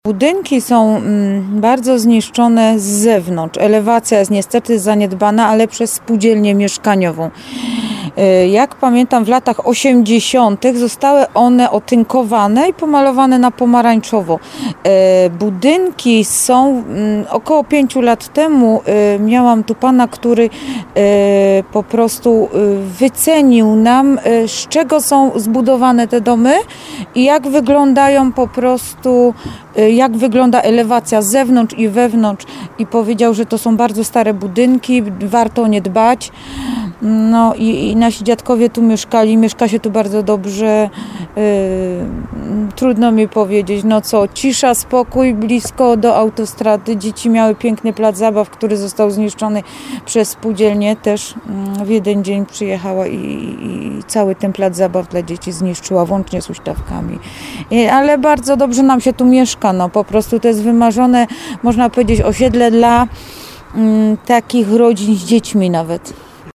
Posłuchaj co powiedziała nam o swoim osiedlu.